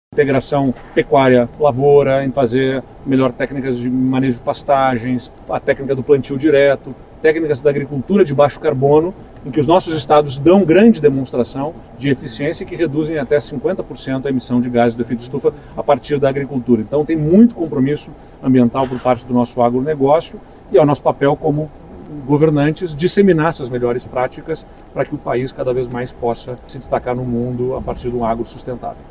Já o governador do Rio Grande do Sul, Eduardo Leite, destacou o papel do agronegócio sustentável e os impactos diretos das mudanças climáticas sobre a produção, com uso de técnicas de baixa pegada de carbono.